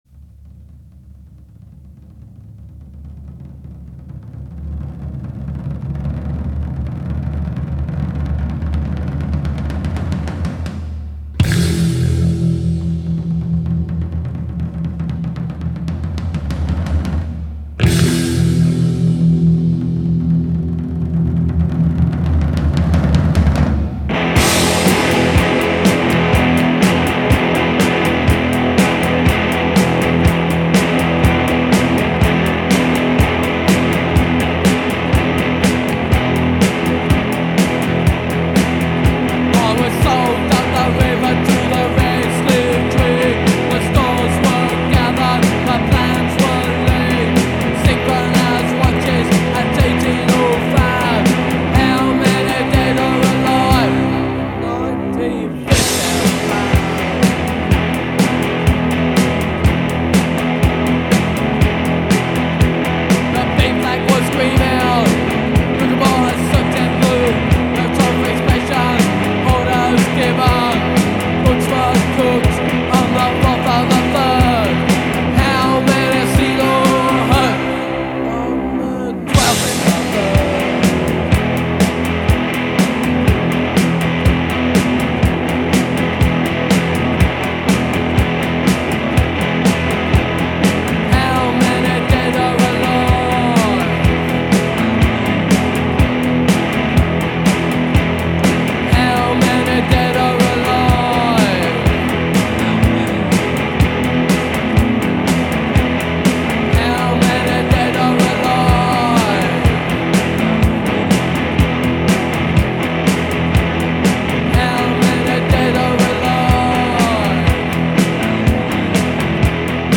It’s a fantastic example of art/punk.
Then stop abruptly.